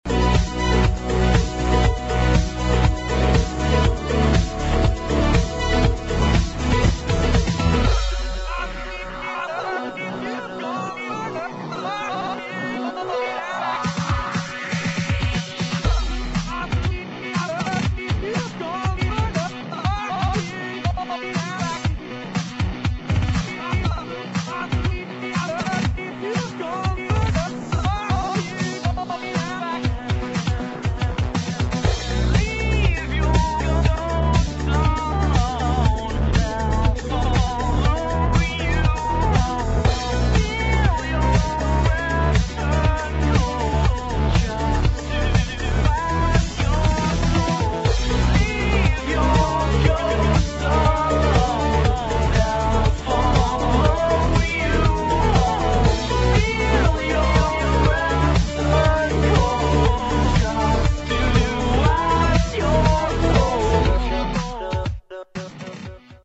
[ HOUSE | ELECTRO ]